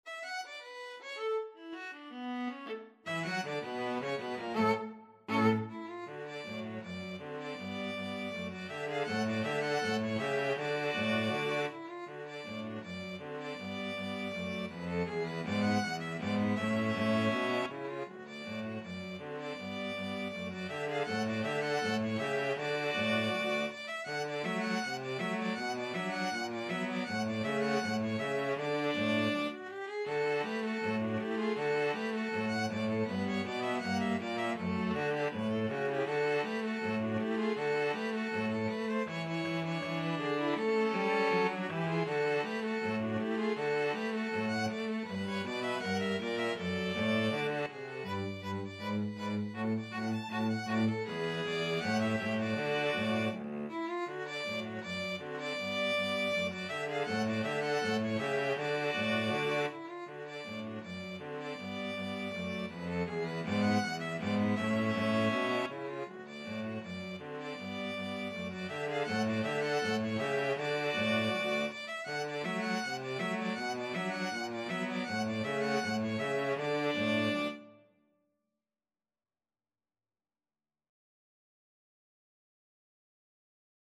Free Sheet music for String trio
ViolinViolaCello
"The Entertainer" is sub-titled "A rag time two step", which was a form of dance popular until about 1911, and a style which was common among rags written at the time.
D major (Sounding Pitch) (View more D major Music for String trio )
=250 Presto (View more music marked Presto)
Jazz (View more Jazz String trio Music)